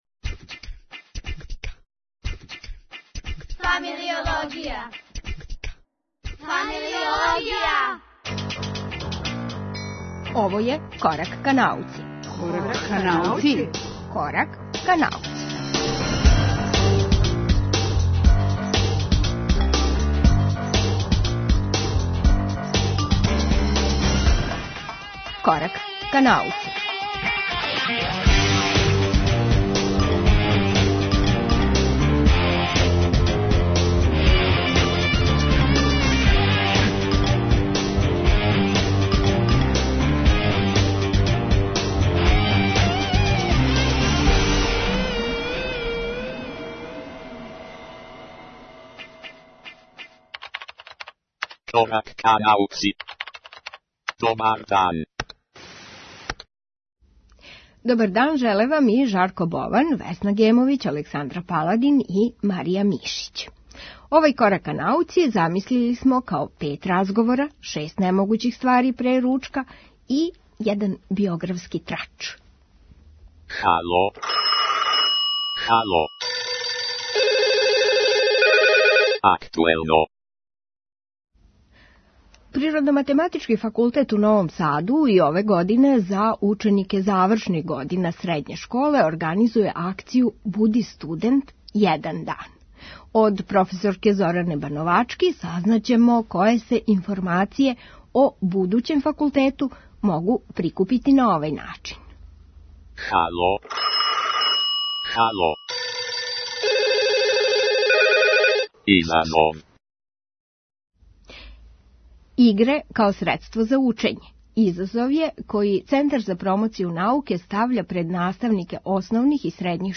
Овај Корак ка науци чине четири разговора, шест немогућих ствари пре ручка и један биографски трач.